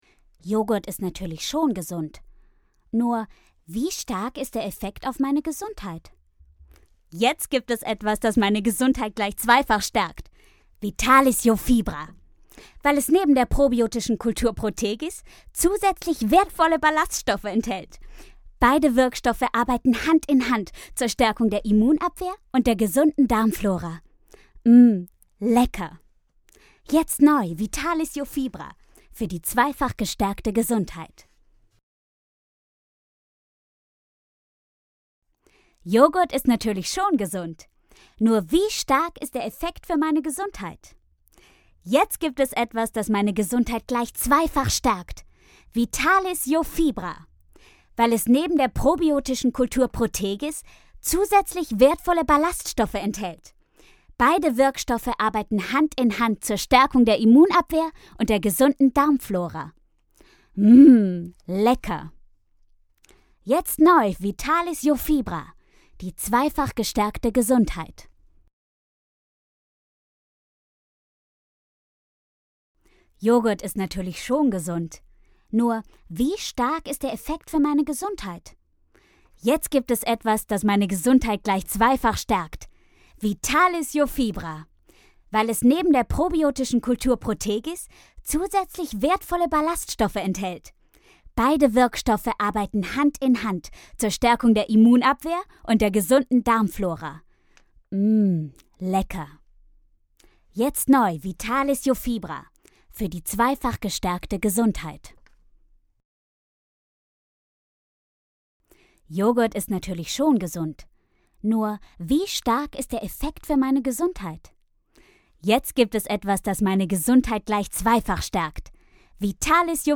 unverwechselbare, spezielle Stimme fĂŒr HĂ¶rspiel, Werbung, HĂ¶rbuch
Sprechprobe: Werbung (Muttersprache):